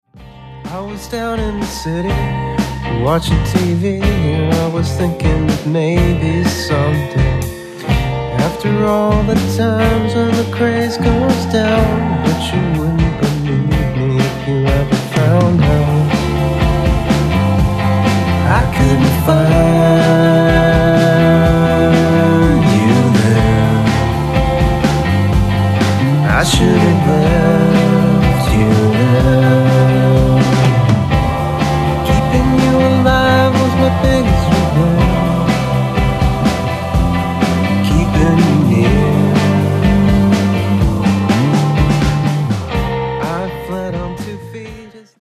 Chapel Hill NC Rock Band